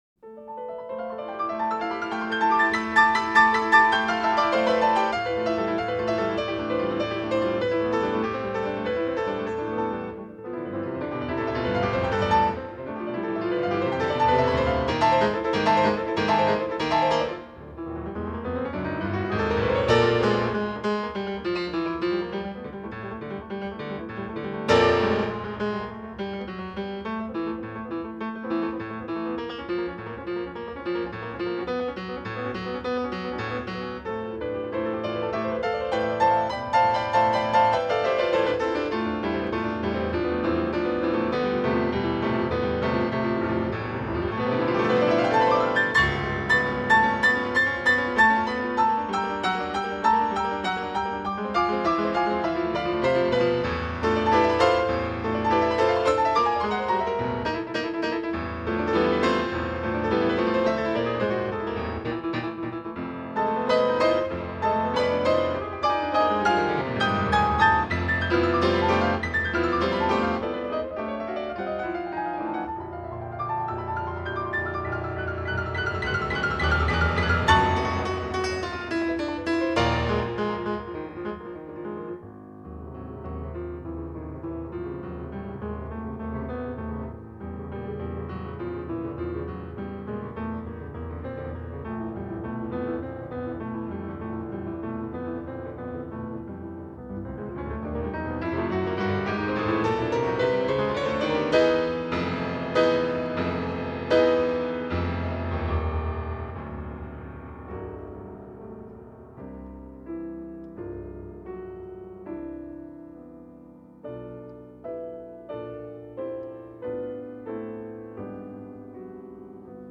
Toccata (extraído de CD). Grabación realizada para el CD de las Muestra de Jóvenes Intérpretes "Ciudad de Málaga" del año 1996 con motivo de la obtención del 2º Premio de la misma.